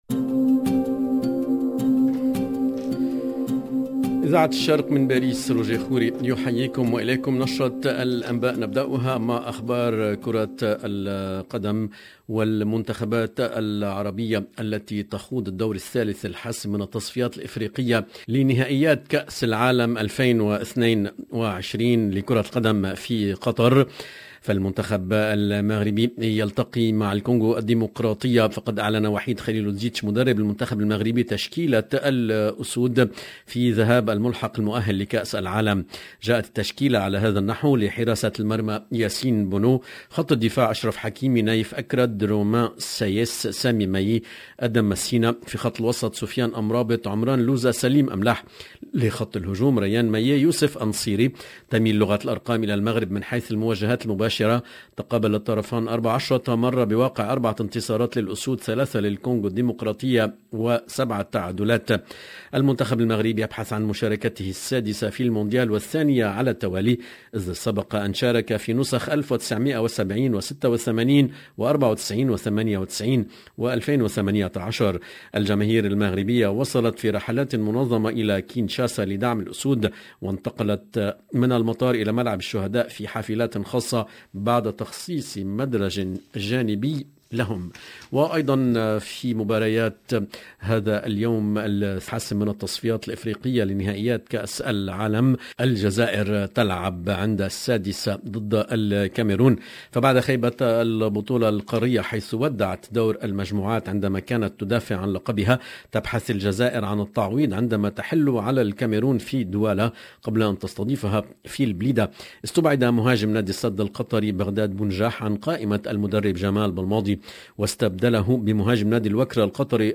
LE JOURNAL DU SOIR EN LANGUE ARABE DU 25/03/22